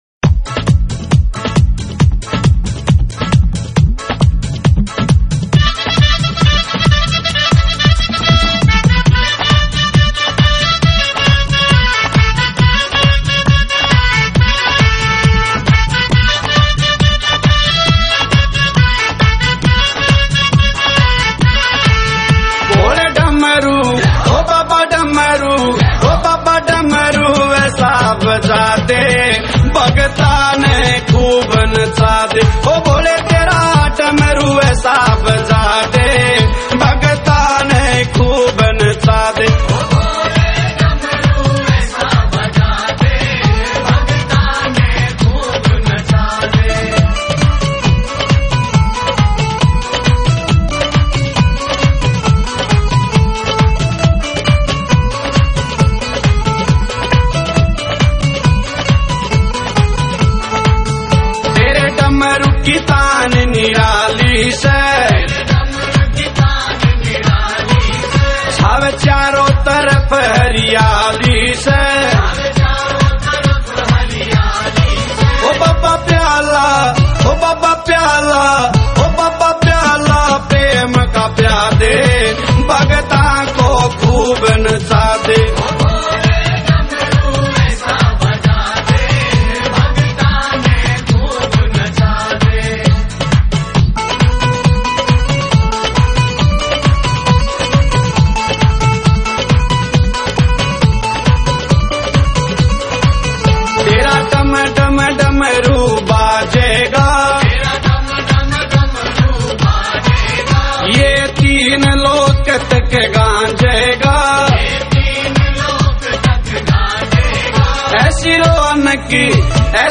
» Bhakti Songs